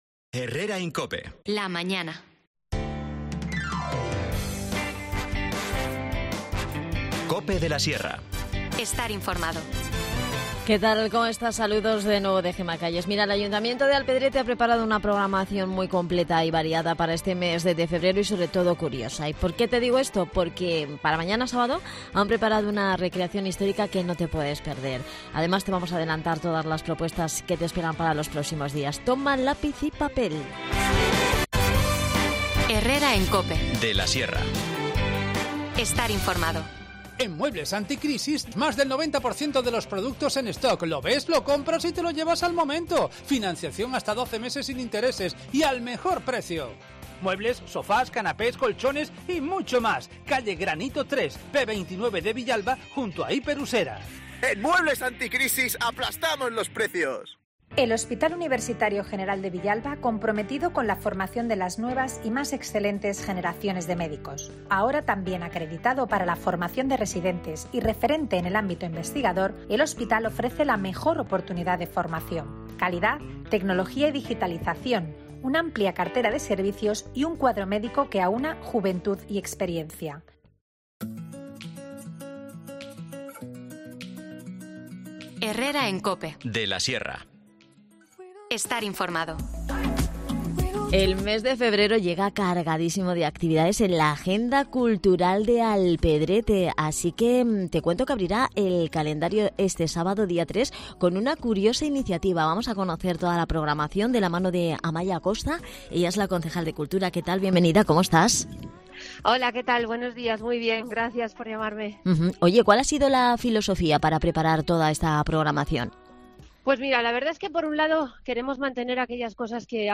Nos detalla la programación Amaya Acosta, concejal de Cultura.